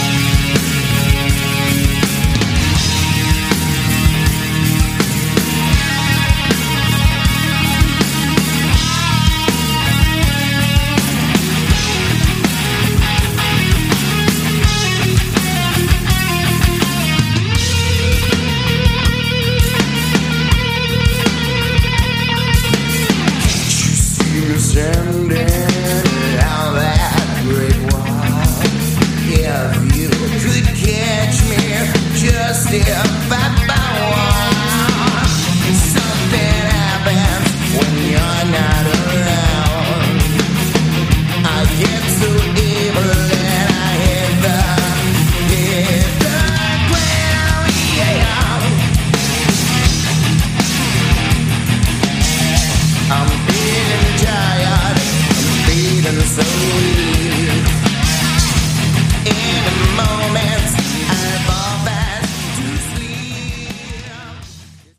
Category: Hard Rock
vocals
lead guitar, backing vocals
drums
bass
guitar
Good Hard Rock & Sleaze Rock